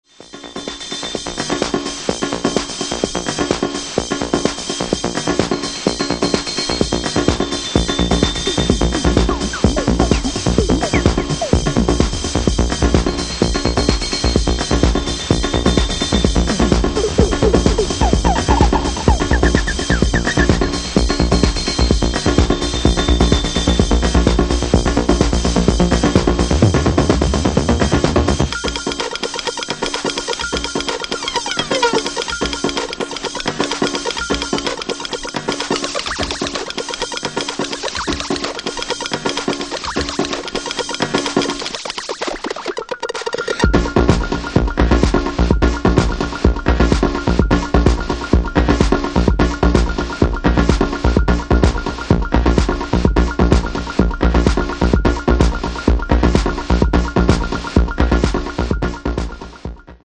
Massive sounds created fior todays soundsystems.